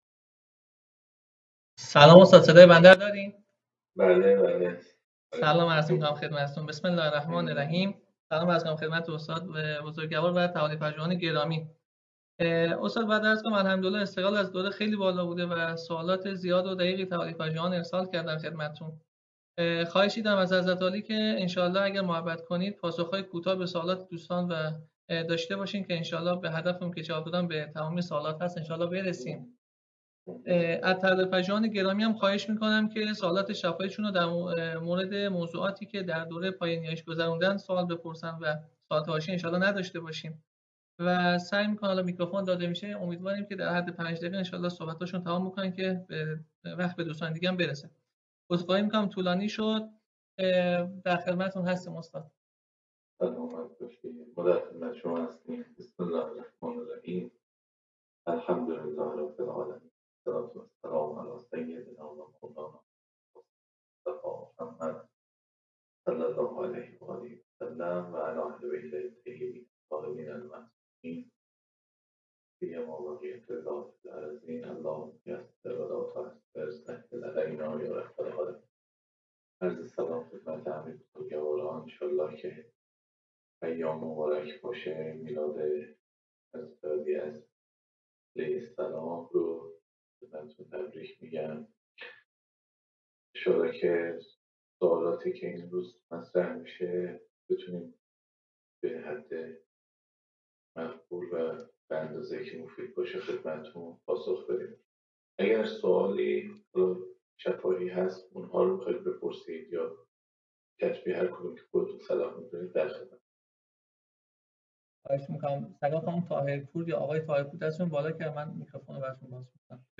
پایه‌ نیایش (احکام عبادات) - جلسه-پرسش-و-پاسخ